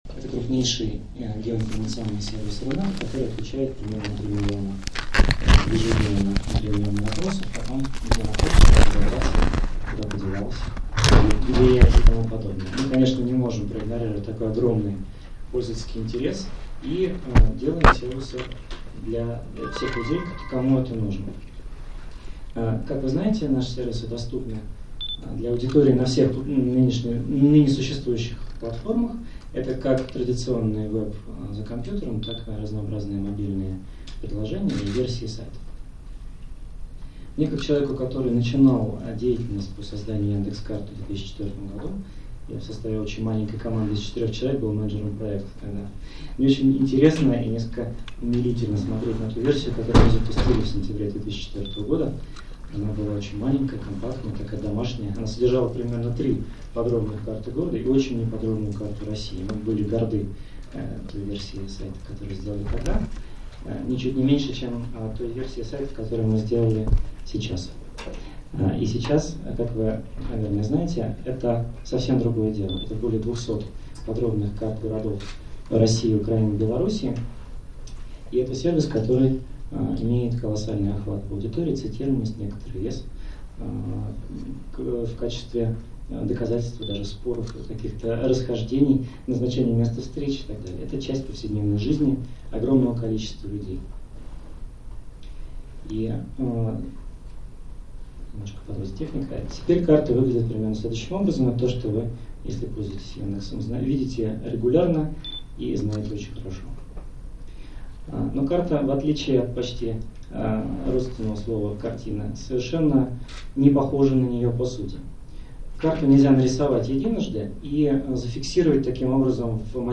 Встреча проходила в конференц-зале “Давос” Swissotel Красные холмы.